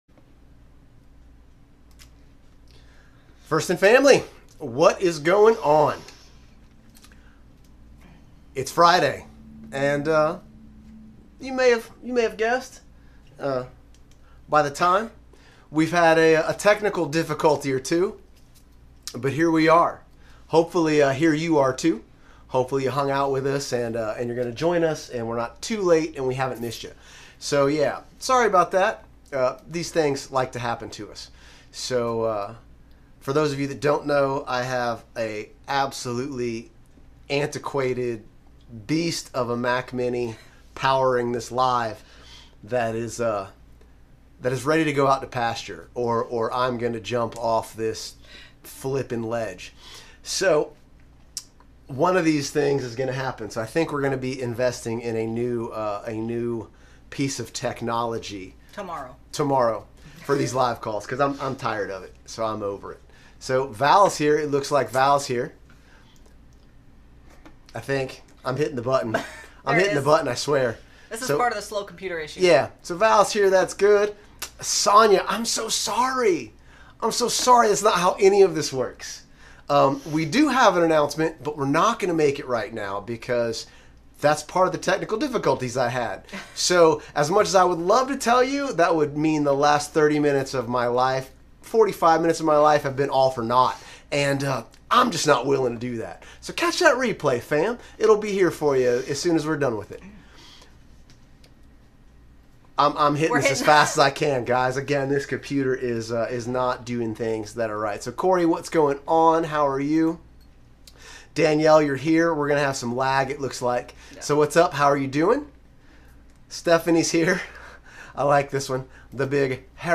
Apologies for the listeners on FIN Live who have to hear us scrolling through all the pics and won't get to see them lol, but this is one you want to see for sure!